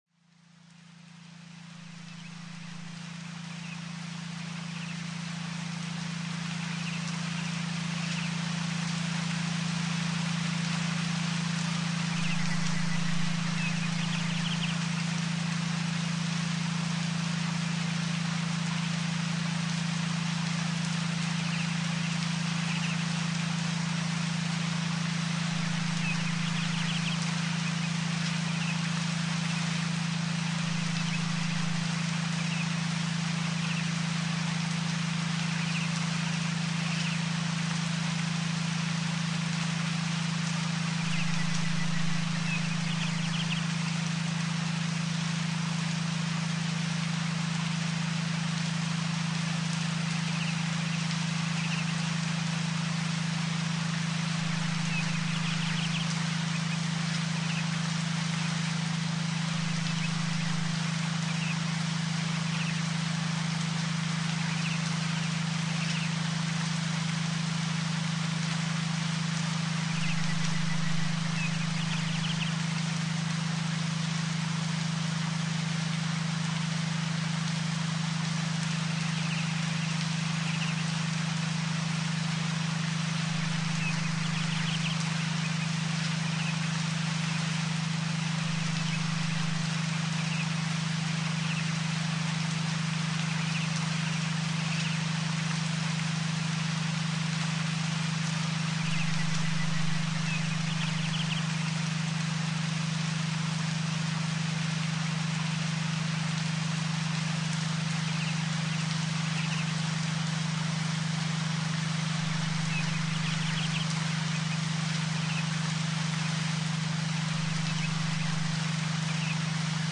alpha-meditation.mp3